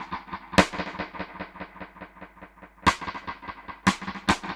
Index of /musicradar/dub-drums-samples/105bpm
Db_DrumsB_EchoSnare_105-03.wav